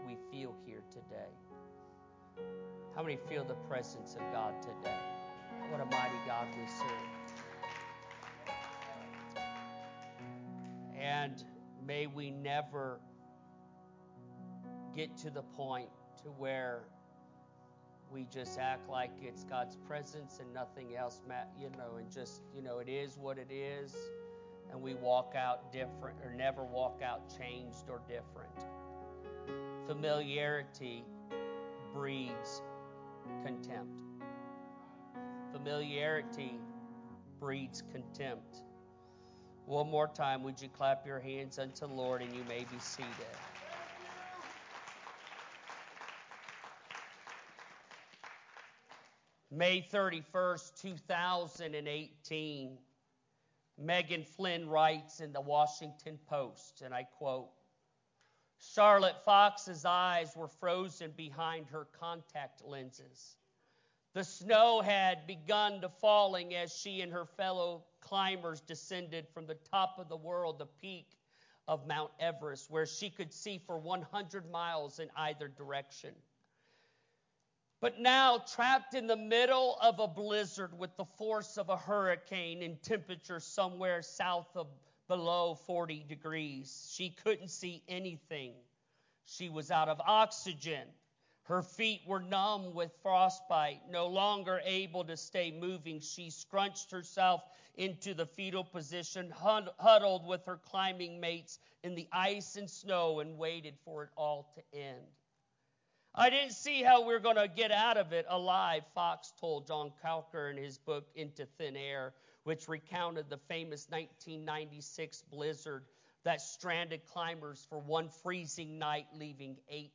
Familiarity-Breeds-Contempt-Sermon-CD.mp3